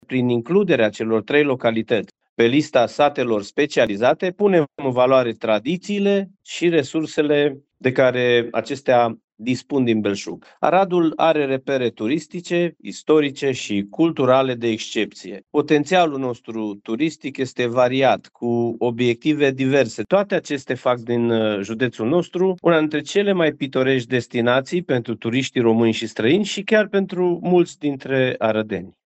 Centrul Național de Informare și Promovare Turistică Arad este primul centru din țară care a solicitat recunoașterea tematică a satelor, spune președintele Consiliului Județean Arad, Iustin Cionca.